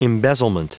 Prononciation du mot embezzlement en anglais (fichier audio)
Prononciation du mot : embezzlement